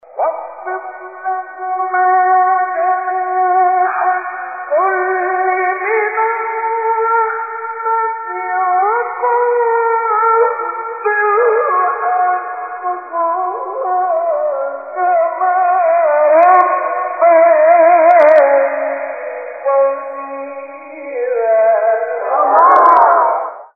سوره : اسراء آیه: 24 استاد : مصطفی اسماعیل مقام : سه گاه قبلی بعدی